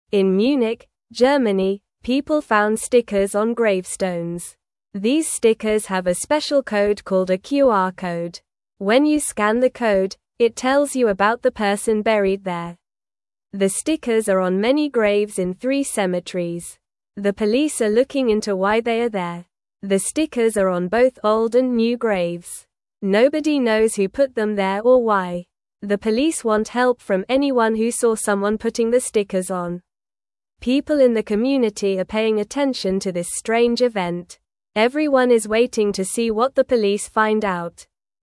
Normal
English-Newsroom-Beginner-NORMAL-Reading-Stickers-on-Graves-Tell-Stories-of-the-Past.mp3